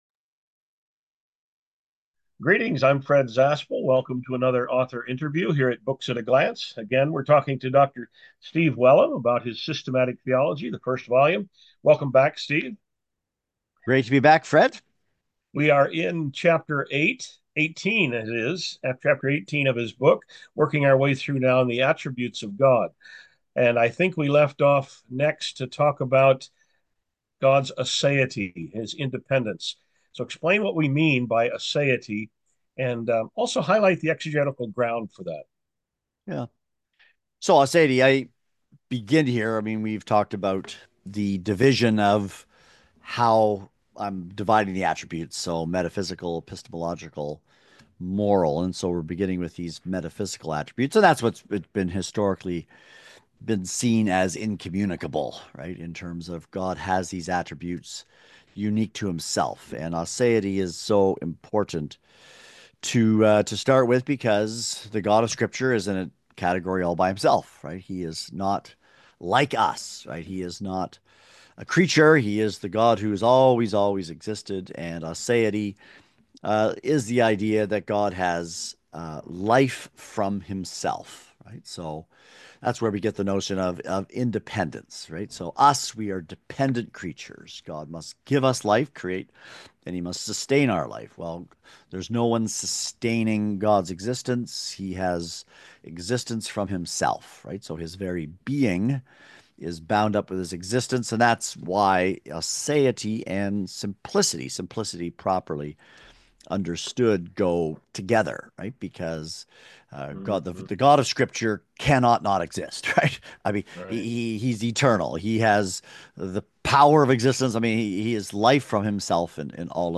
An Author Interview from Books At a Glance
Sample Audio Interview: